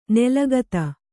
♪ nelagata